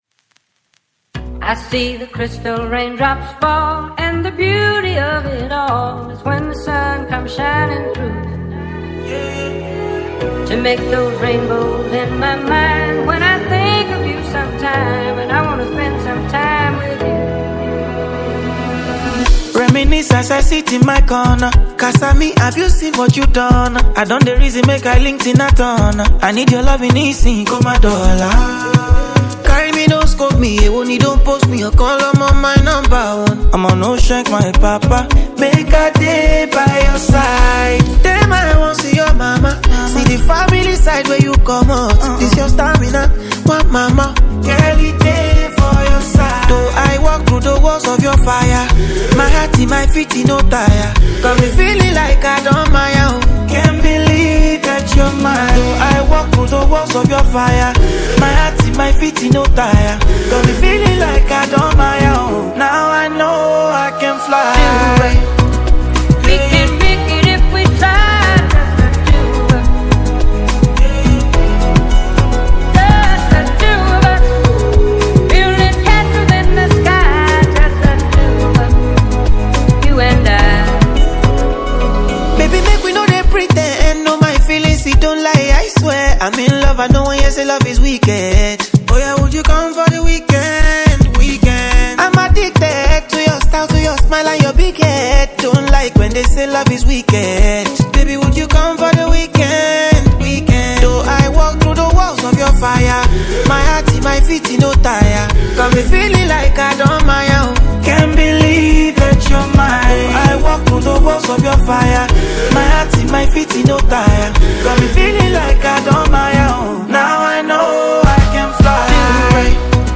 The song blends Afrobeat rhythms with soulful melodies
signature smooth vocals and uplifting lyrics